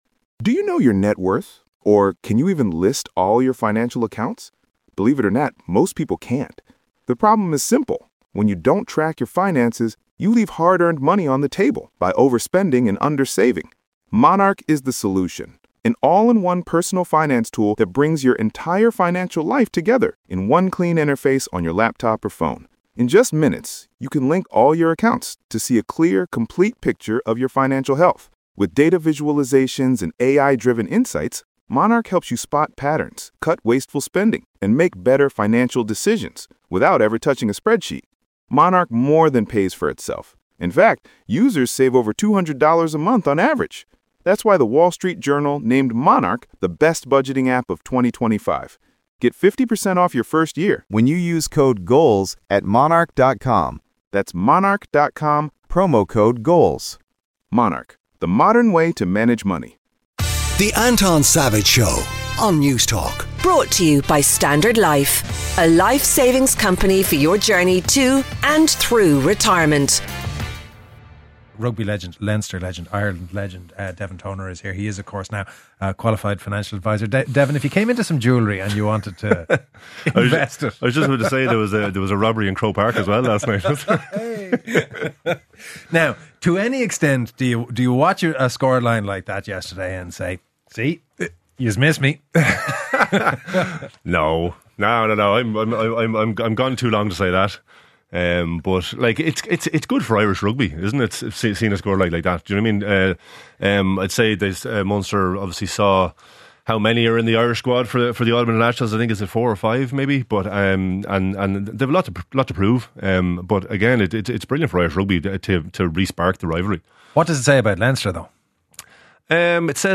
In conversation with Devin Toner